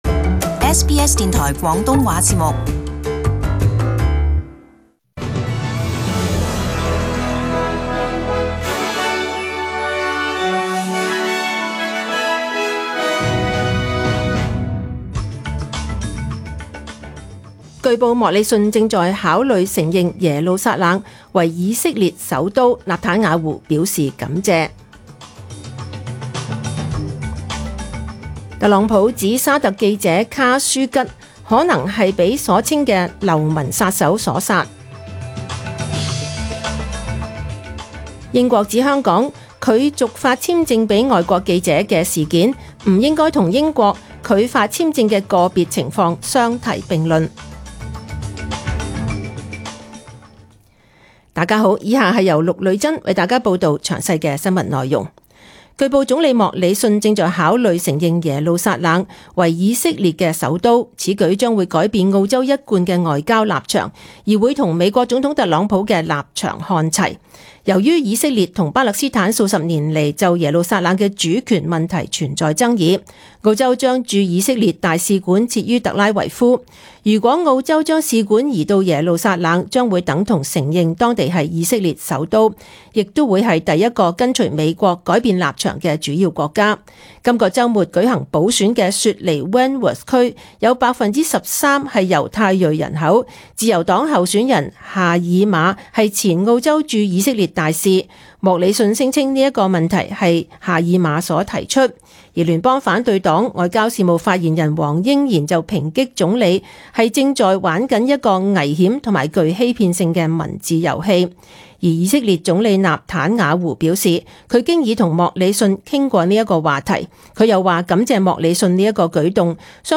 SBS中文新聞 （十月十六日）